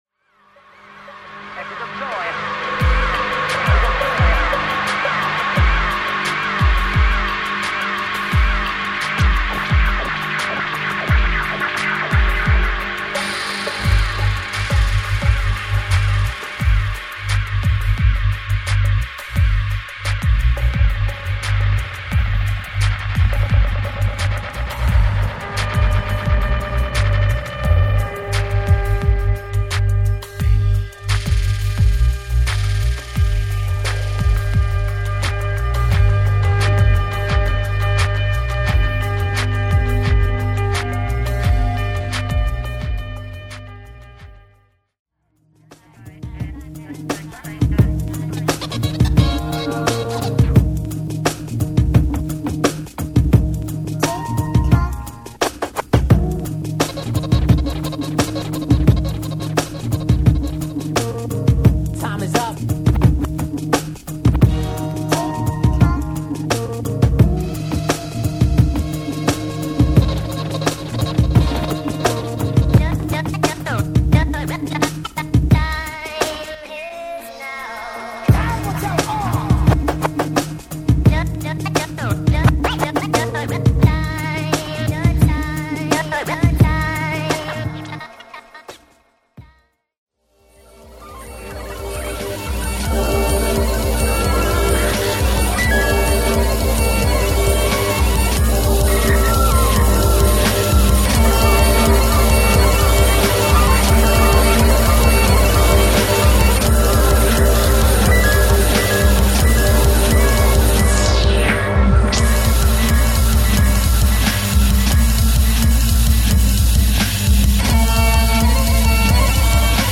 JAPANESE / MIX TAPE / NEW RELEASE(新譜)